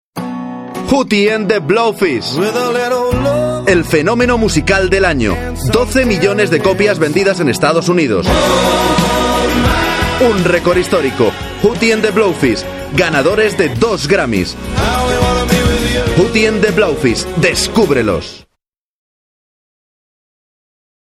Voces Masculinas